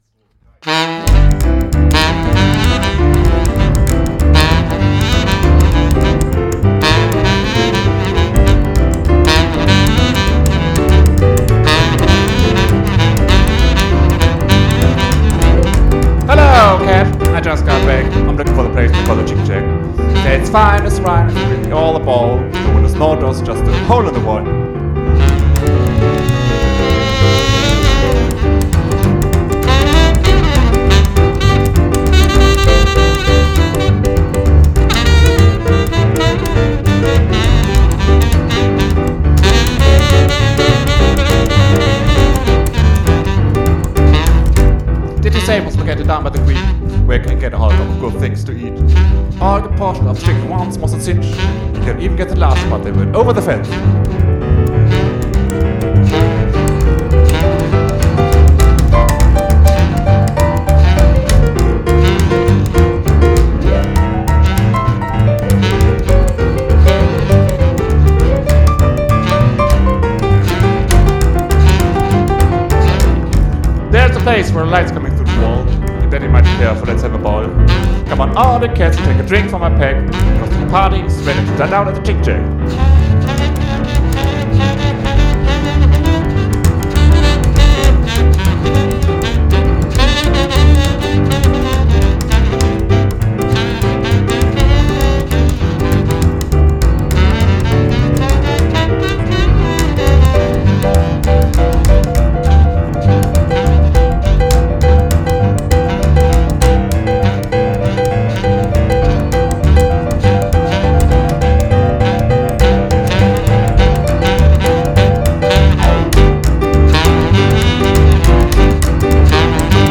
Rock 'n' Roll im Stil der 1950er
Kontrabass
Gitarre
Schlagzeug
Piano
Saxophon